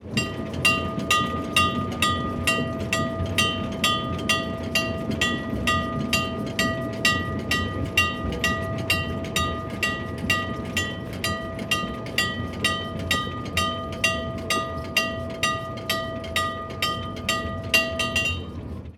Old Train Bell Sound
transport
Old Train Bell